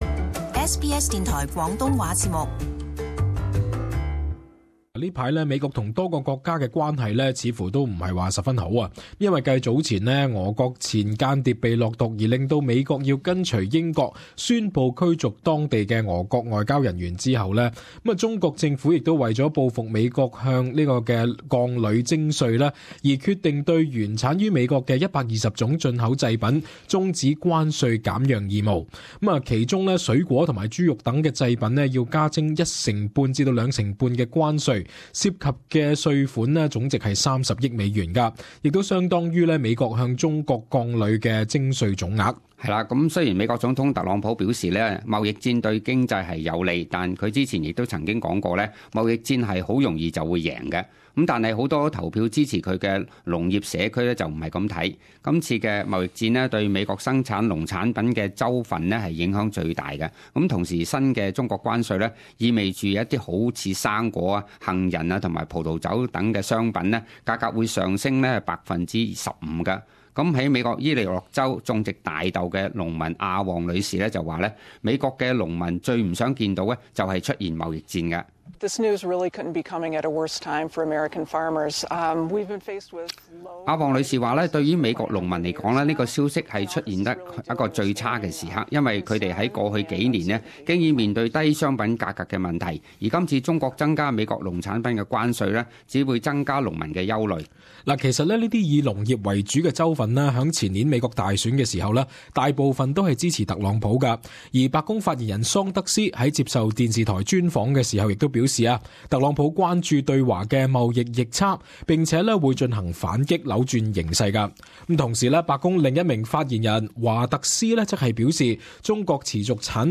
【時事報導】中國向美國加收關稅 澳洲或從中受惠